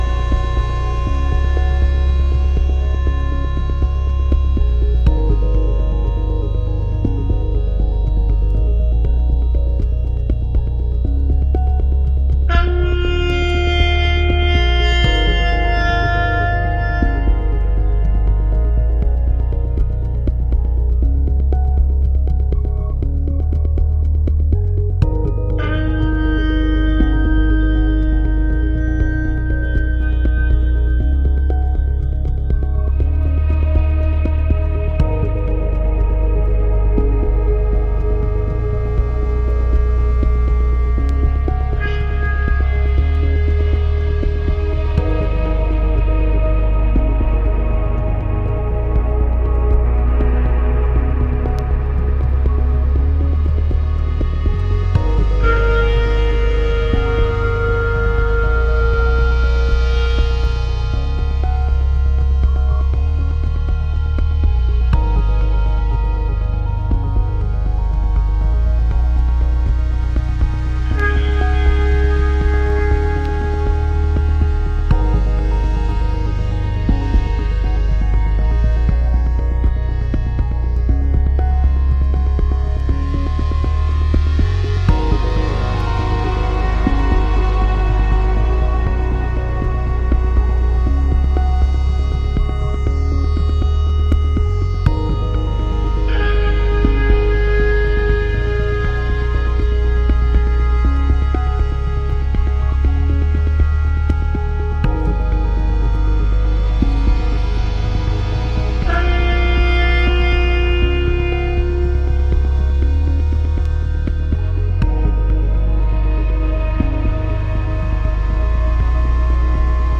sublime, writhing
House Acid